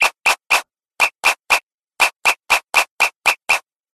3・3・7拍子（さんさんななびょうし）【拍手とホイッスル】 着信音
三三七拍子のリズムの効果音です。